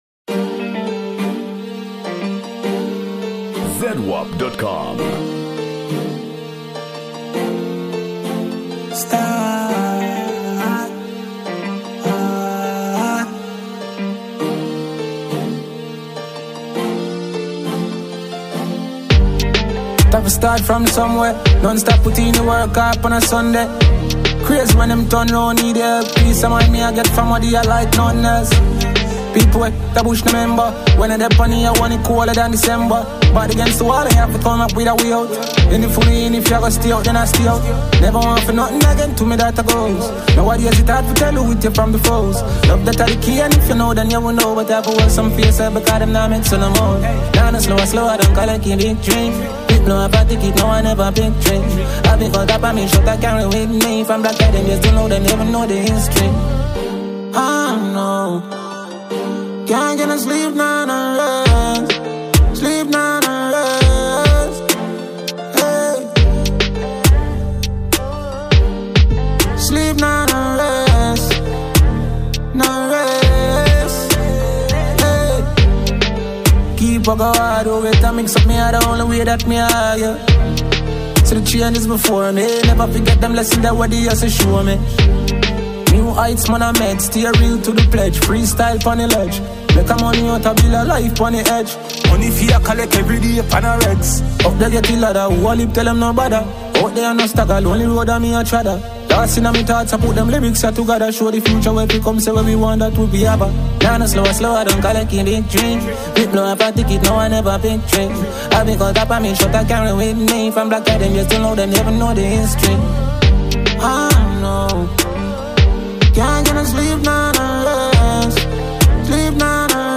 Jamaican dancehall singer